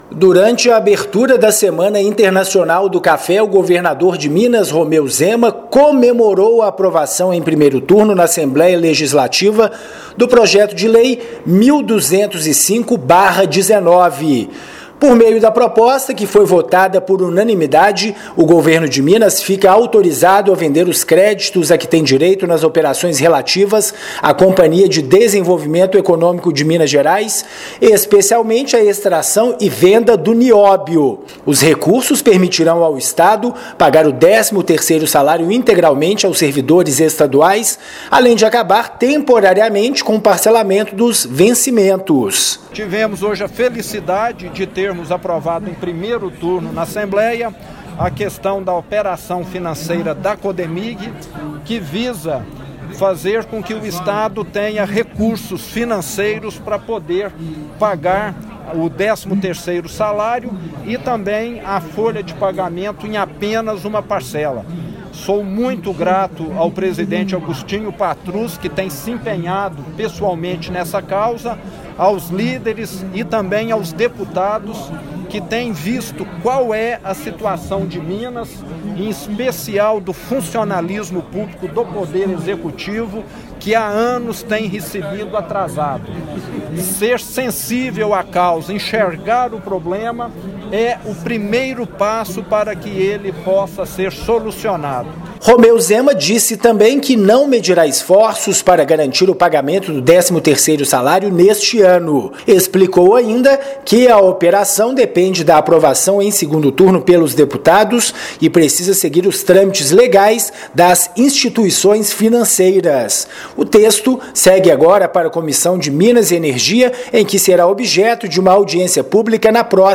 Aprovado em primeiro turno pelos deputados, projeto busca antecipar os recebíveis que a Codemig tem referentes à extração de nióbio. Ouça a matéria de rádio.